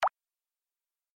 button_click.mp3